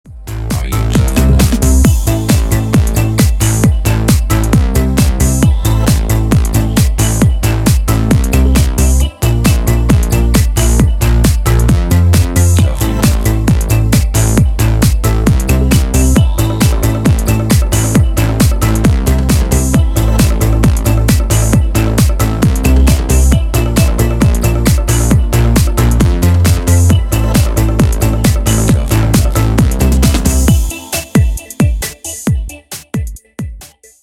• Качество: 192, Stereo
dance
Electronic
без слов
club
90-е